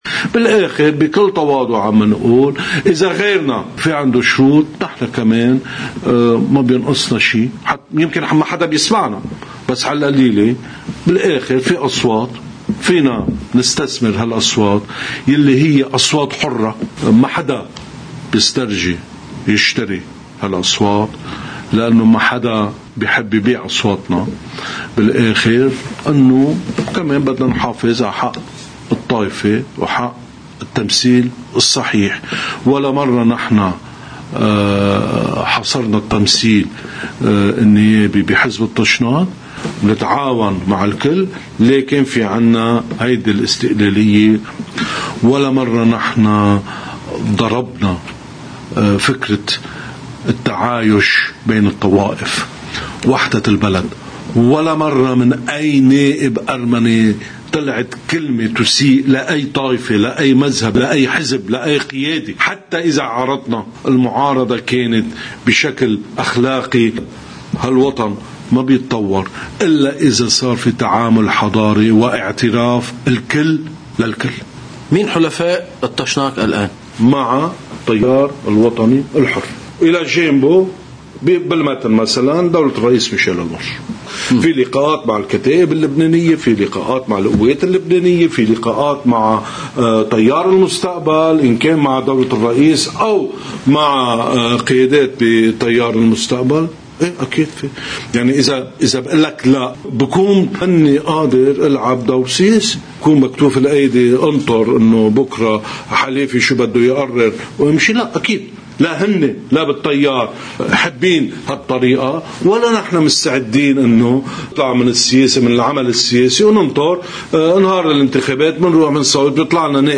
مقتطف من حديث أمين عام حزب الطاشناق أغوب بقردونيان لقناة الـ”OTV”، ضمن برنامج “يوم جديد”: